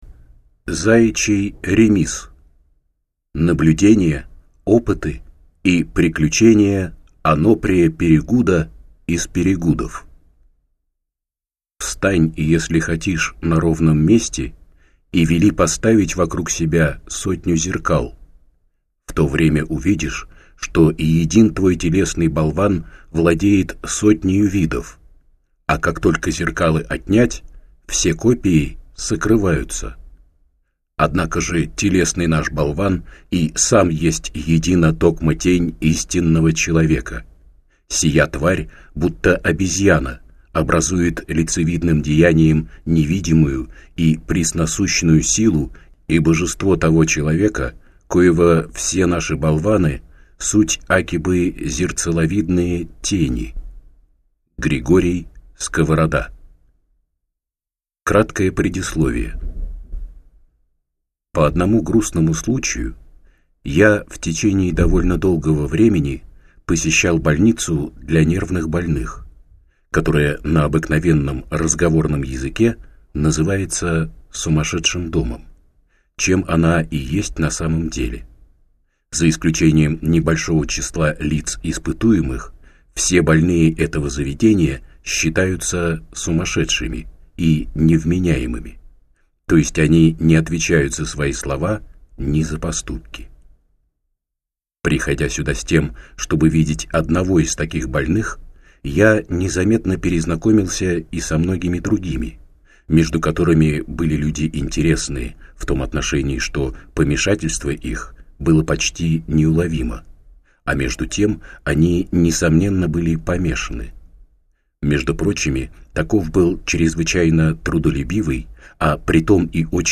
Аудиокнига Заячий ремиз | Библиотека аудиокниг